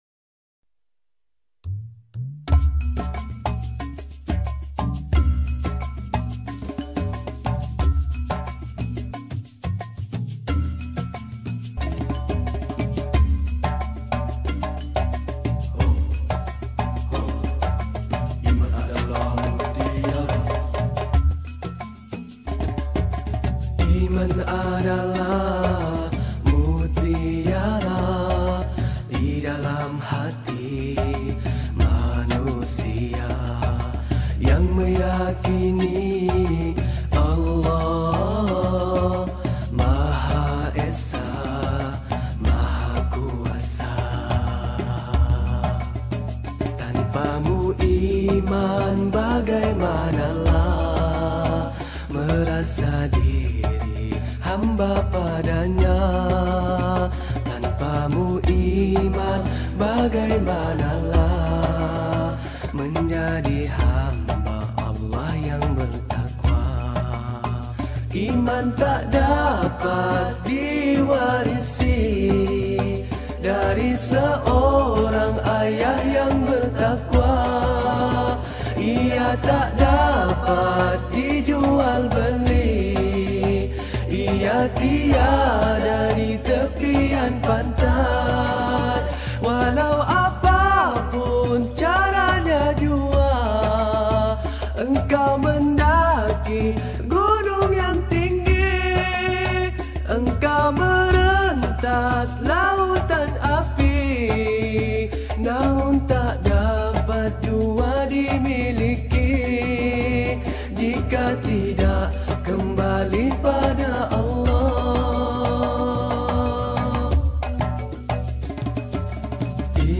Nasyid Songs
Lagu Nasyid